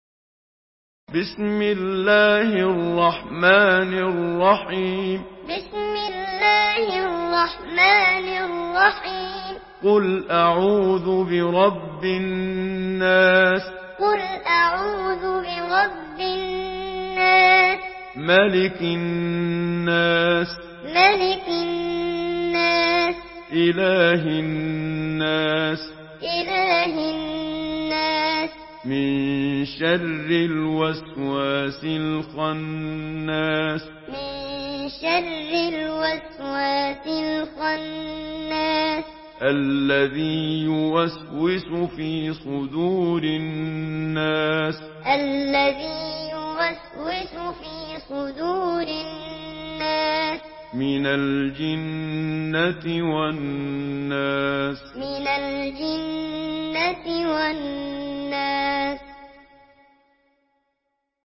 Sourate An-Nas MP3 à la voix de Muhammad Siddiq Minshawi Muallim par la narration Hafs
Une récitation touchante et belle des versets coraniques par la narration Hafs An Asim.